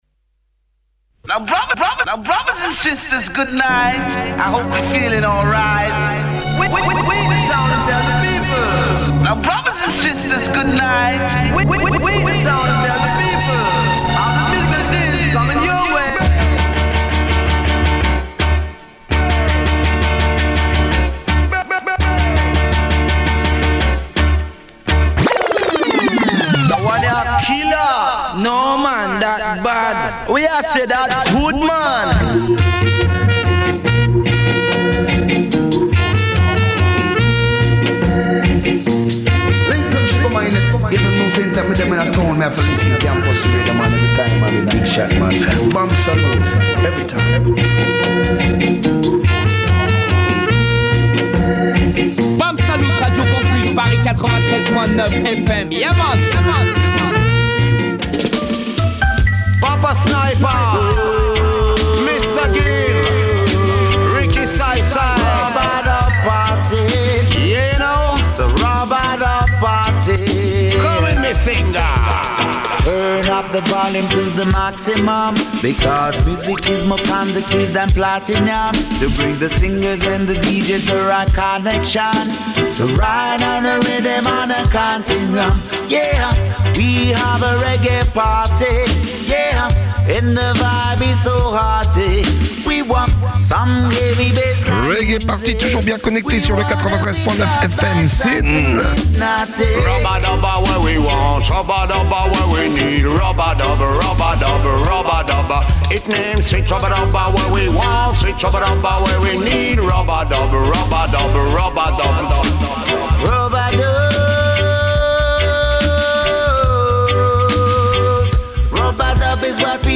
une sélection spéciale Ladies
Hip-hop